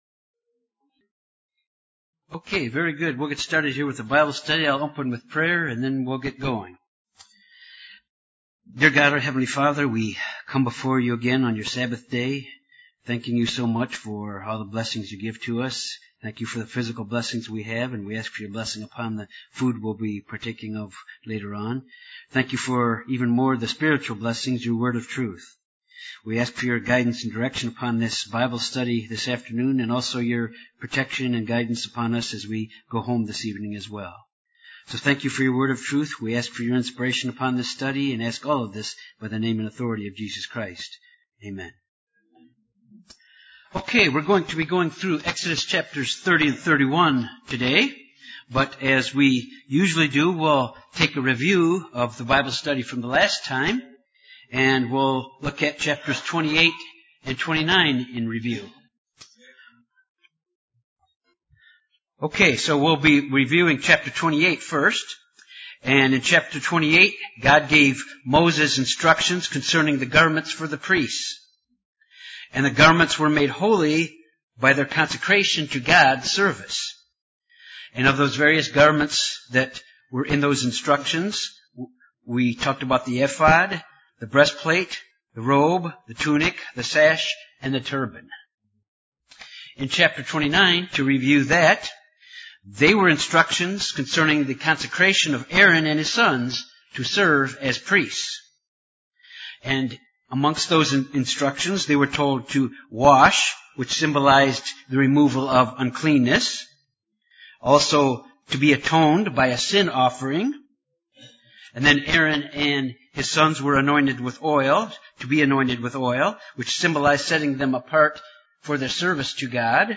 This Bible study examines the completion of the furnishing and incense of the tabernacle. It covers the completion of the instructions God gave to Moses during the forty days while Moses was on Mount Sinai.